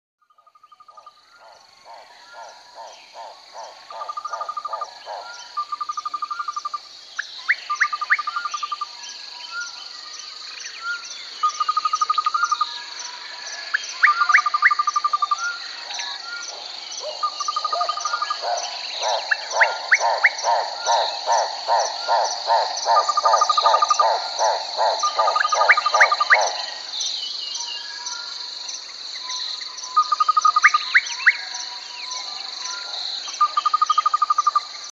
Zvyku_lesa.mp3